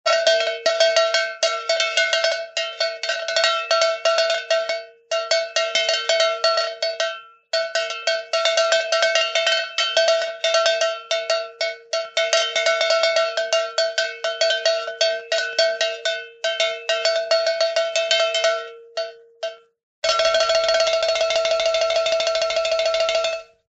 Звуки металла
Звук удара по металлическим предметам